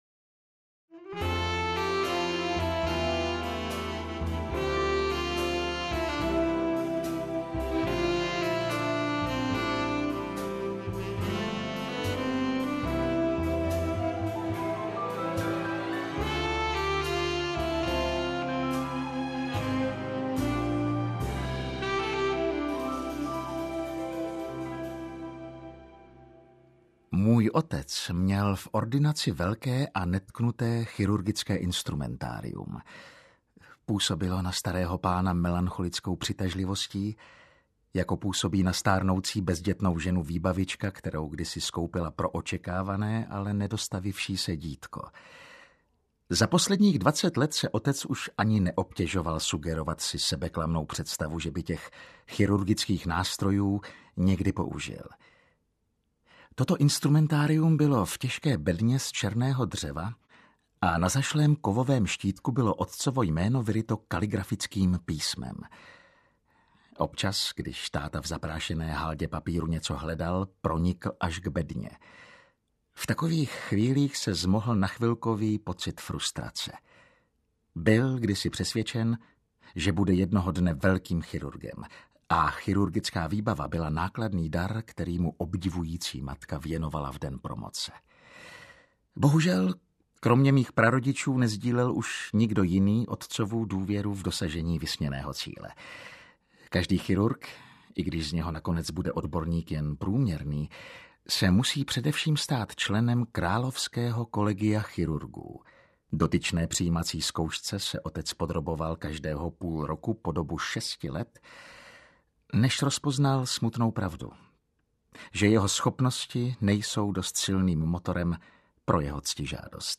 První svazek z populární série knih vyprávějících humorné historky ze života mediků, lékařů a jejich pacientů. Zde jako audiokniha.